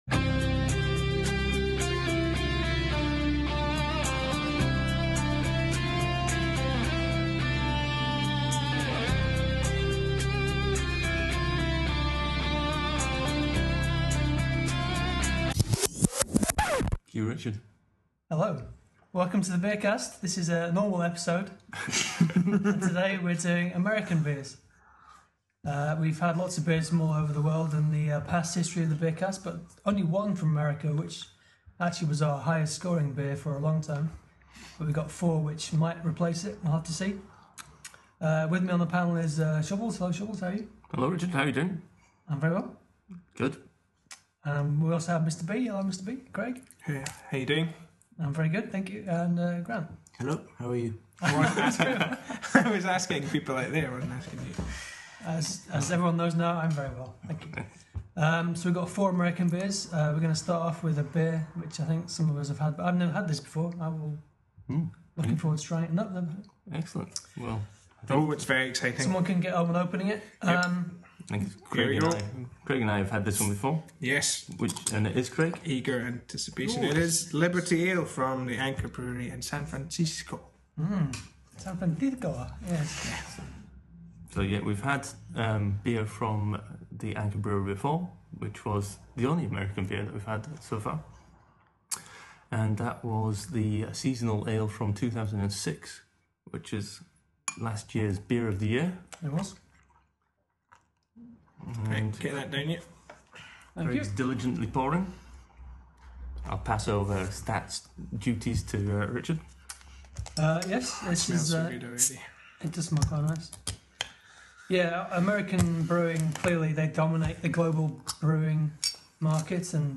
Fittingly, our 21st podcast heads over the pond to America – where that particular number has great significance in the beer world. Our panel get to grips with four US beers, one of which could potentially have deteriorated on the journey over the Atlantic.